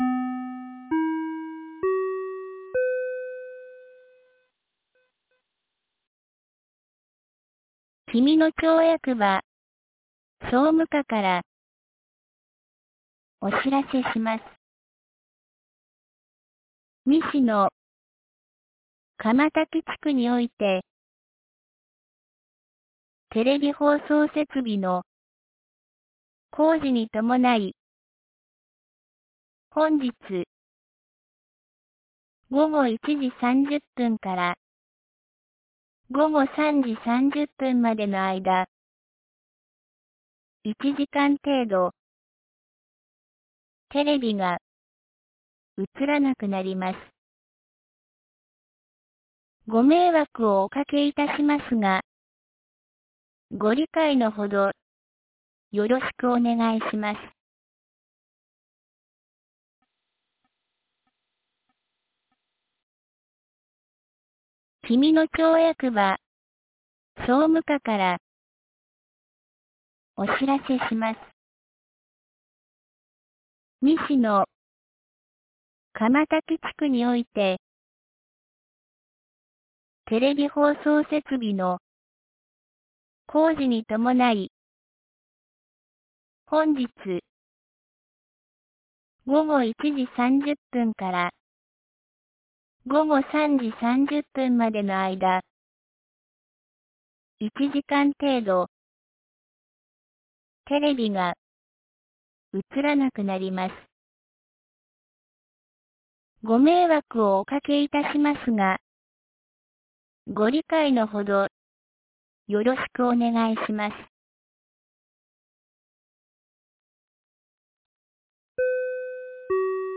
2023年12月15日 12時42分に、紀美野町より志賀野地区へ放送がありました。